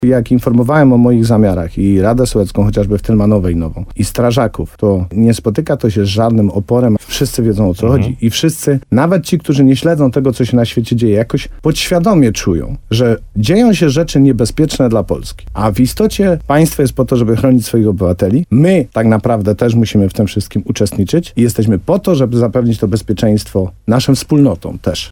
Jak dodaje wójt, wśród mieszkańców panuje duże zrozumienie tego tematu.